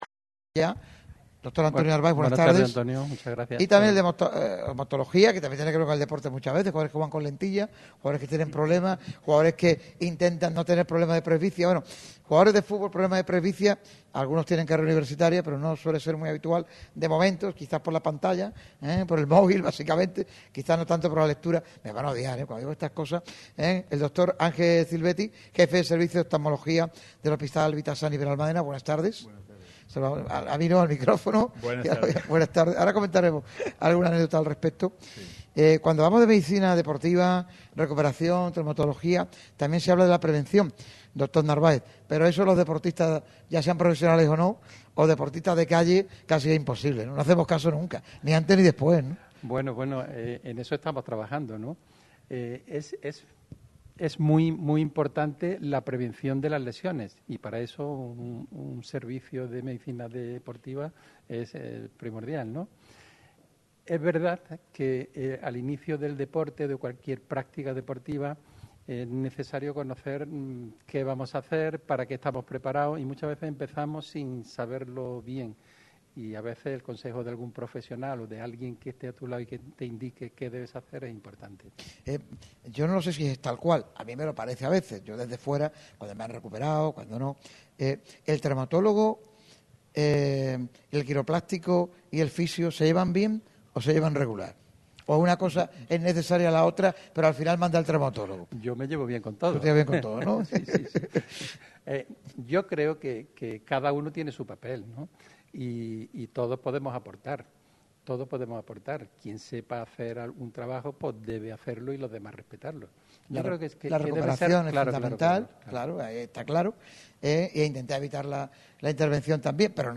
Una charla en la que el peso de las lesiones en el mundo del fútbol ha tenido mucha presencia.